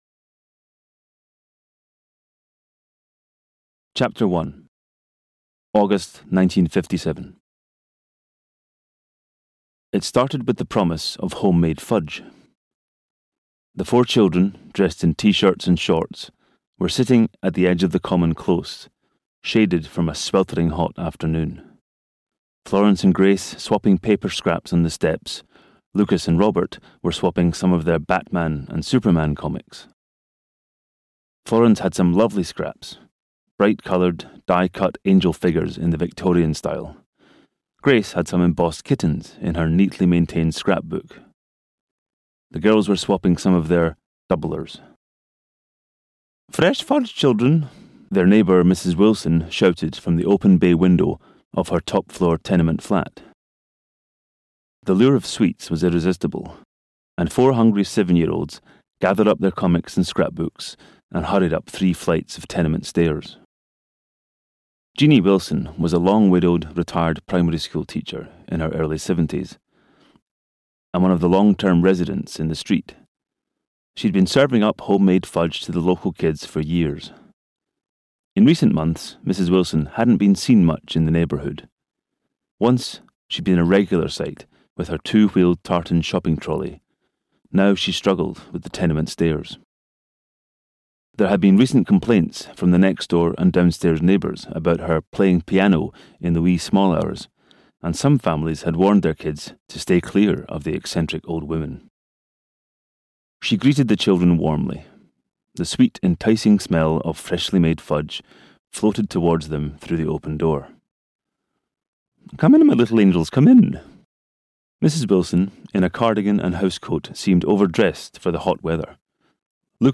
The Fear of Falling (EN) audiokniha
Ukázka z knihy